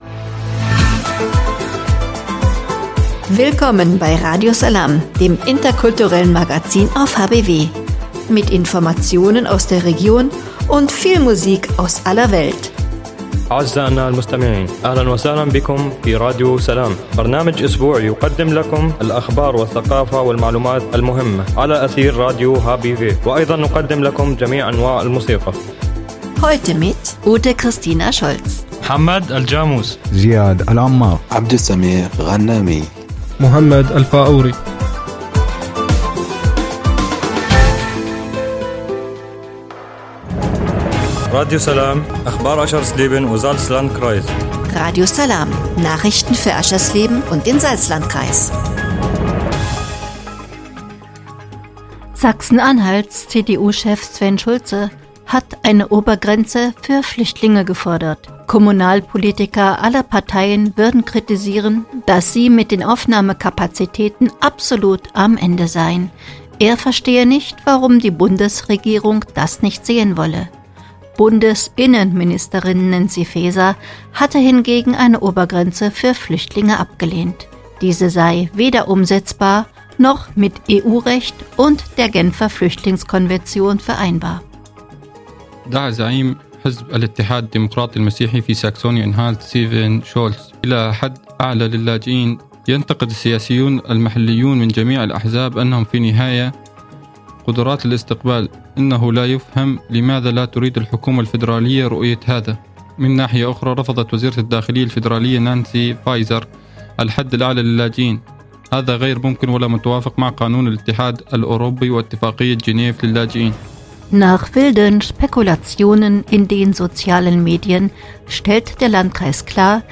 „Radio Salām“ heißt das interkulturelle Magazin auf radio hbw.
(Hinweis: Die in der Sendung enthaltene Musik wird hier in der Mediathek aus urheberrechtlichen Gründen weggelassen.)